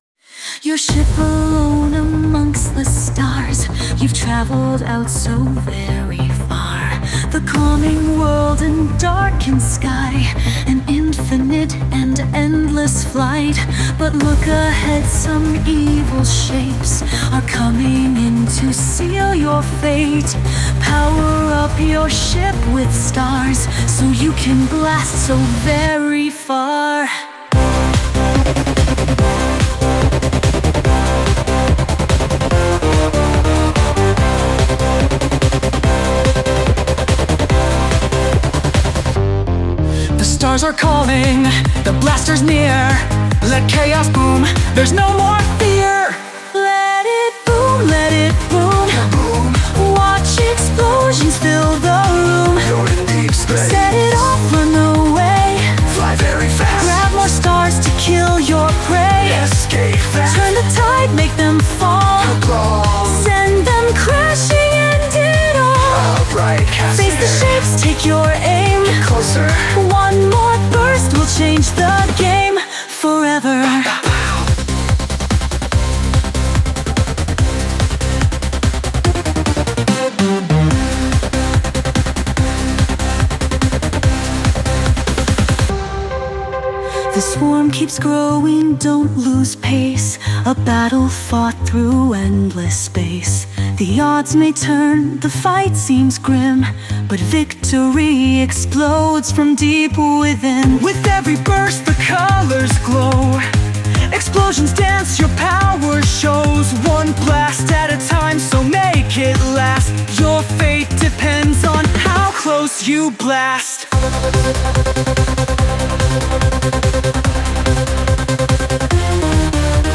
Anime version
Sung by Suno